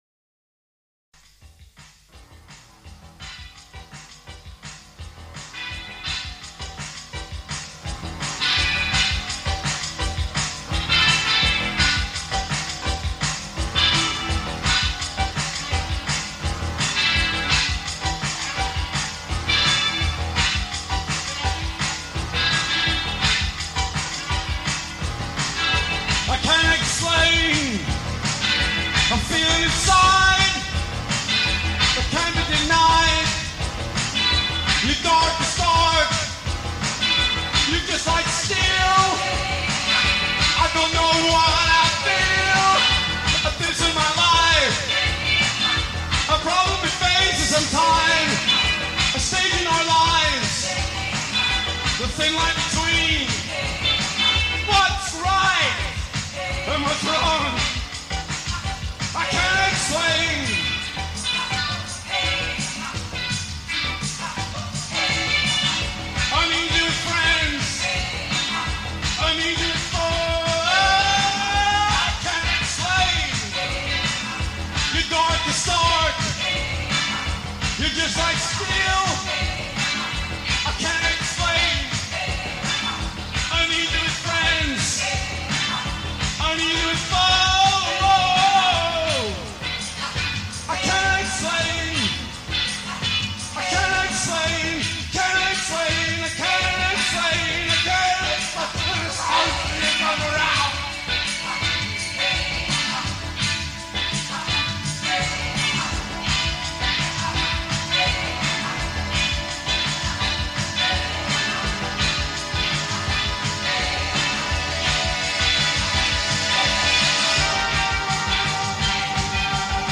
vocals
keyboards